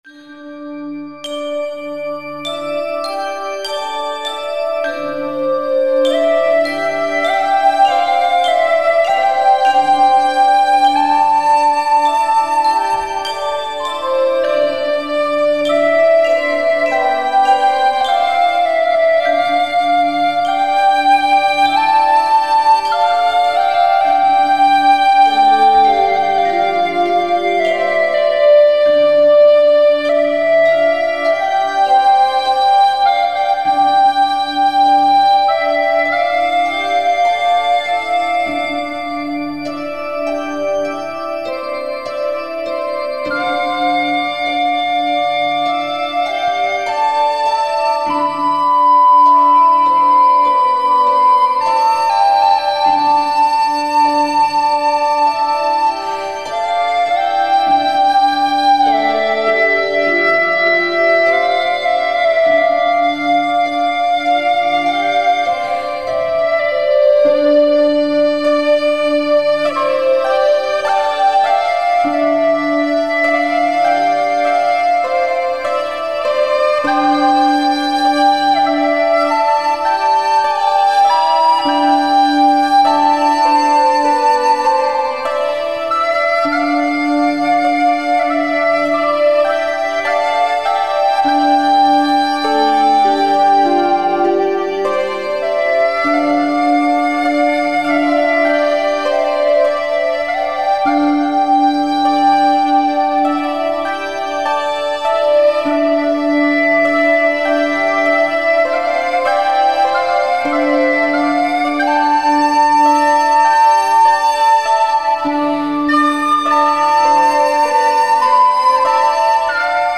• Жанр: Фолк
Instrumental